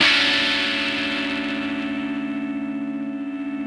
ChordDmaj9.wav